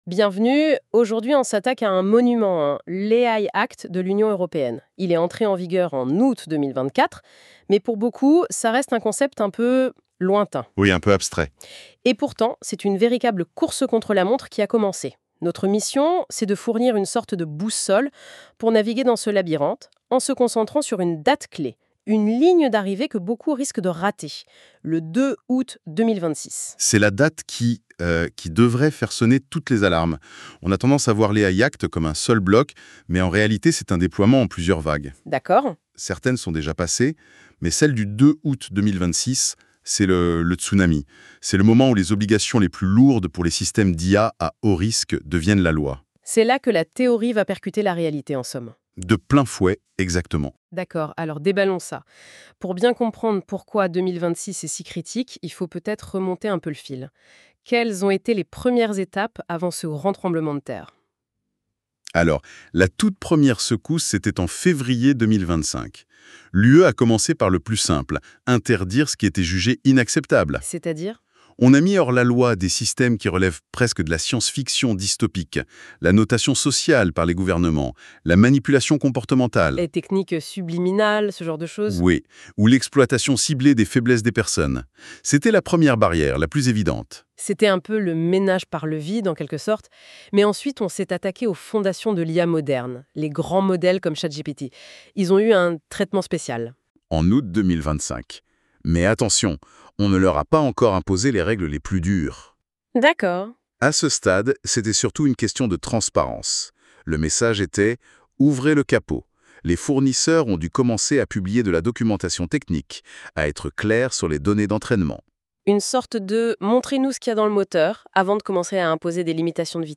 Écoutez cet article en podcast
Version audio générée par NotebookLM